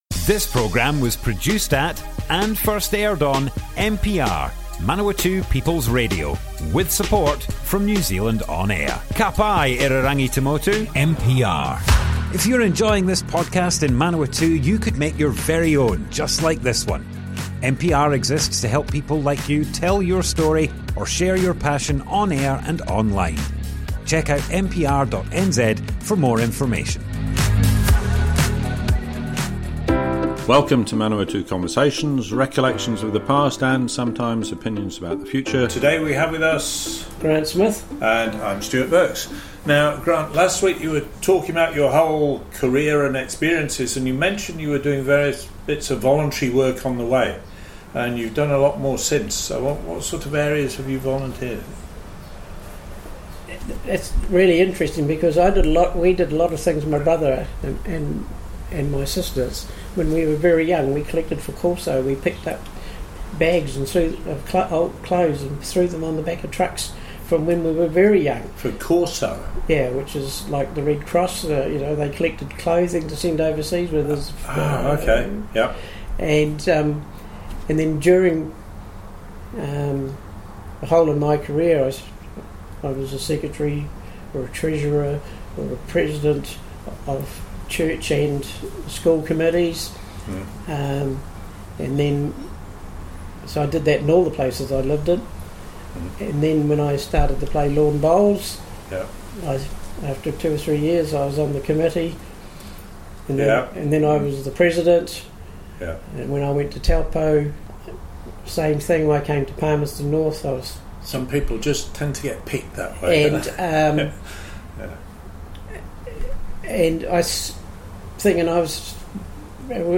Manawatu Conversations More Info → Description Broadcast on Manawatu People's Radio, 14th January 2025.
oral history